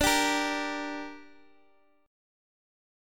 Dsus4#5 chord